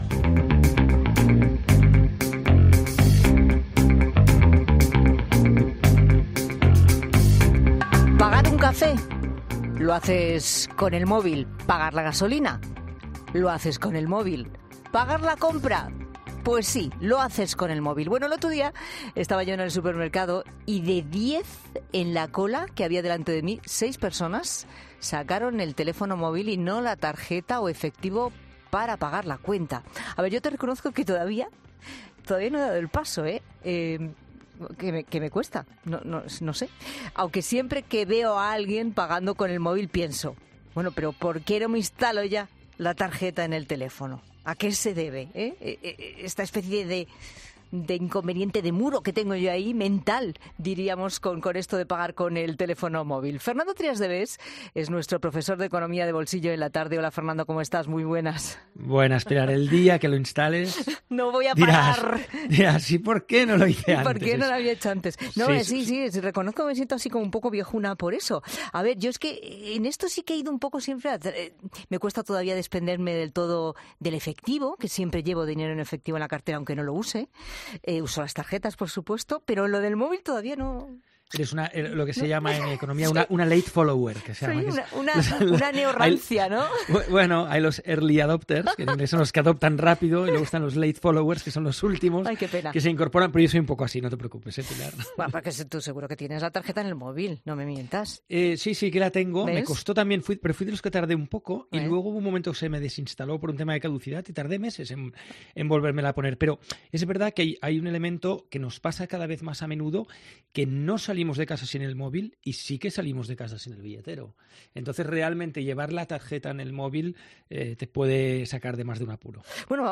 En 'La Tarde', analizamos con el economista Fernando Trías de Bes, ventajas inconvenientes de ambos modelos